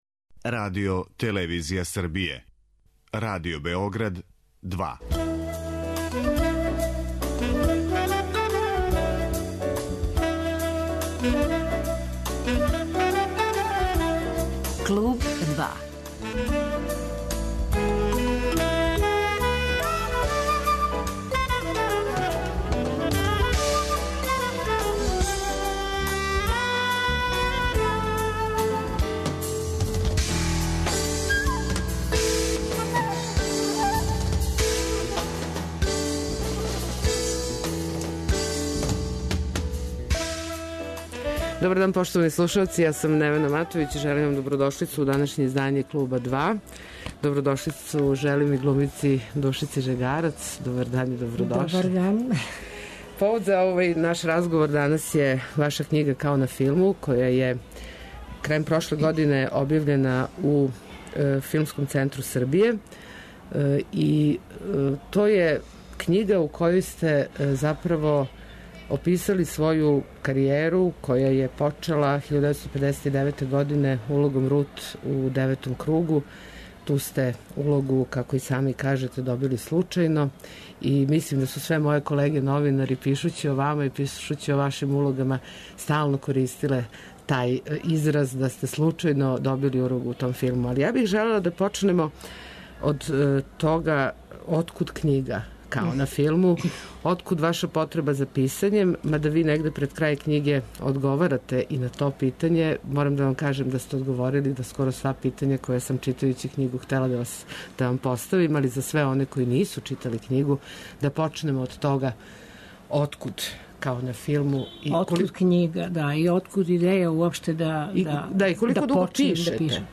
Гошћа је глумица Душица Жегарац